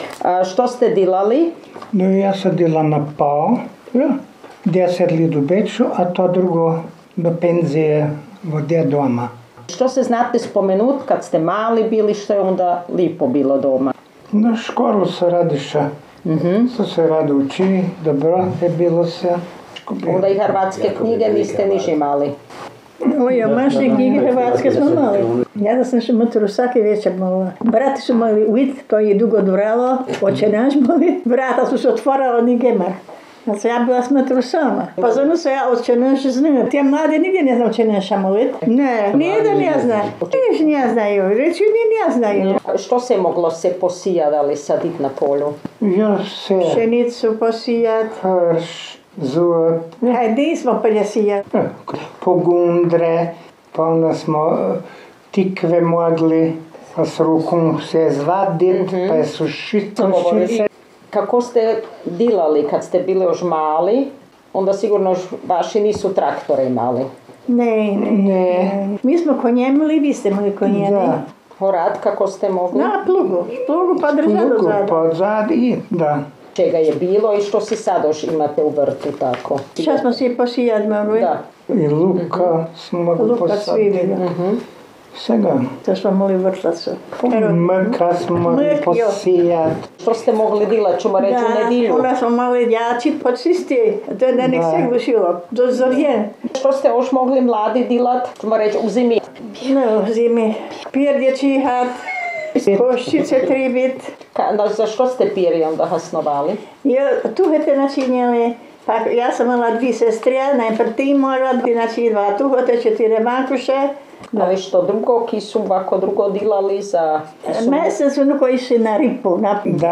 jezik naš, jezik naš gh dijalekti
Žarnovica – Govor
Heugraben im Burgenland
48_Zarnovica_govor.mp3